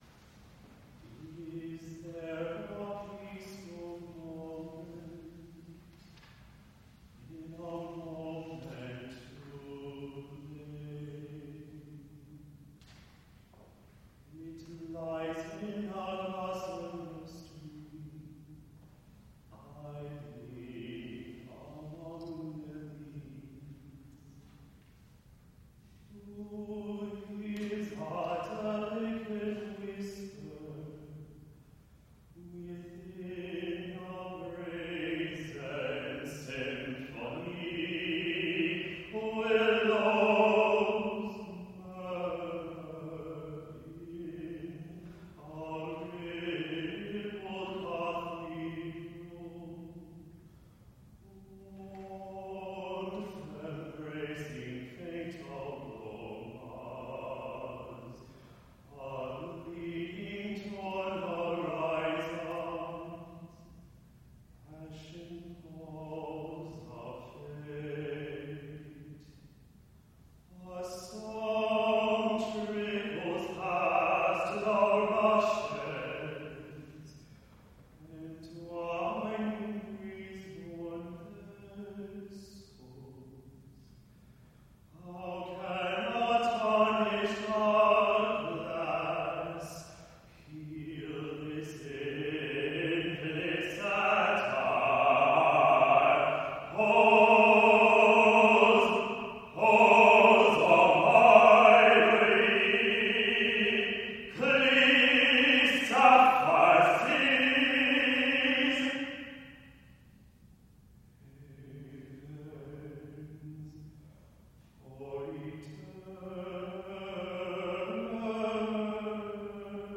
solo baritone voice
Evocalise-for-Solo-Baritone.mp3